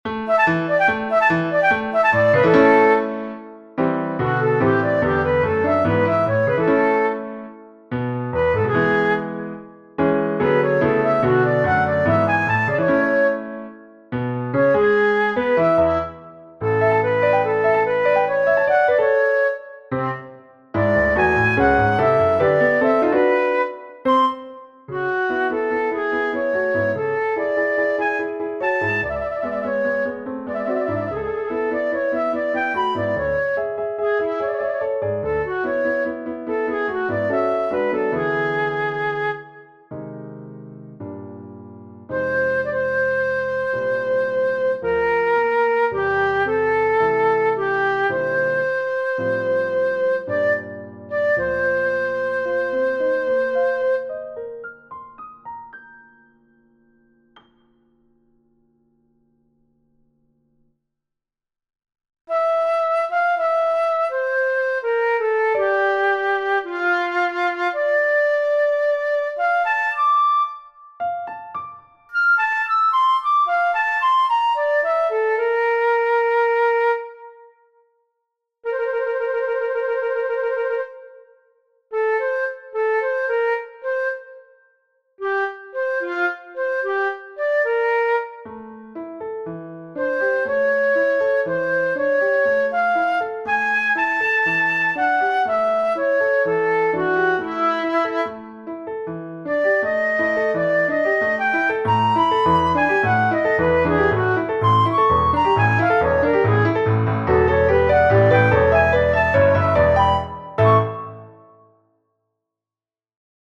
Two players - no more, no less.
Flute and Piano Number 3 MS